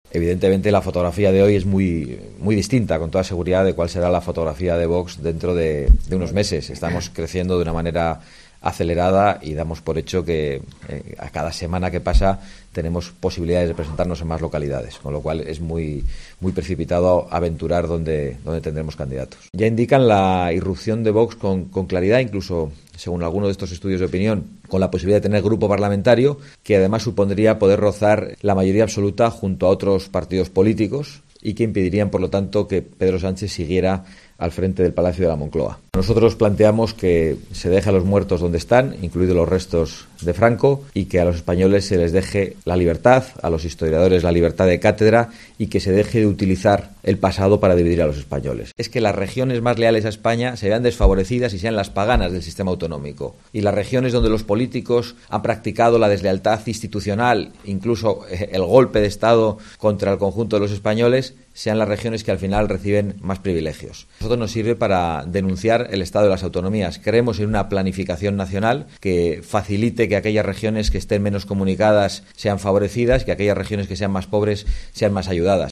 Declaraciones de Santiago Abascal, presidente de VOX, en su última visita a Extremadura